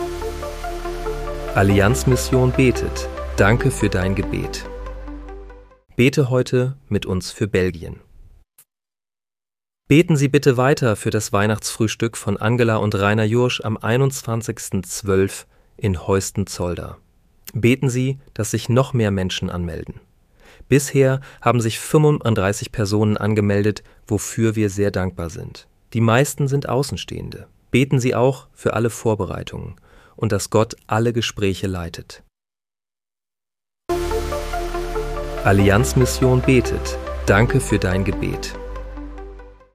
Bete am 19. Dezember 2025 mit uns für Belgien. (KI-generiert mit